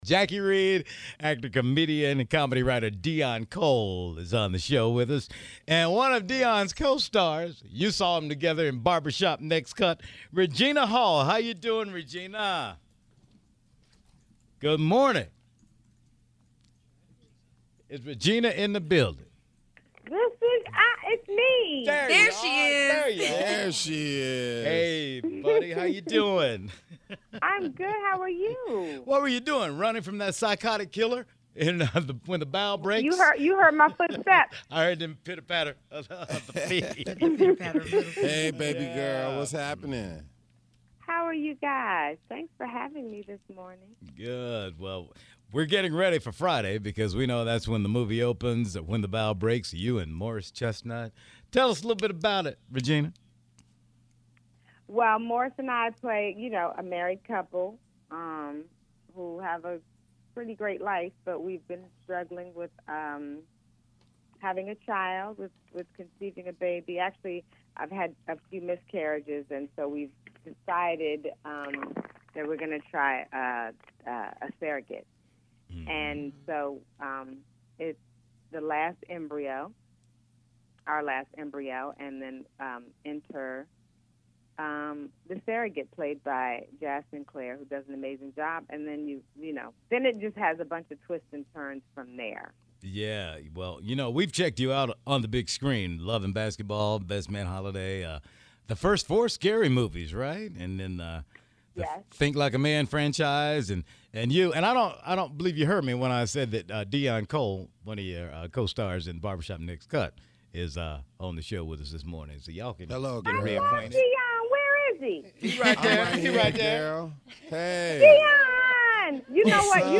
Regina talks to the Tom Joyner Morning Show about the film and the roles she played opposite some of Hollywood’s funniest men.